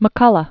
(mə-kŭlə), David Born 1933.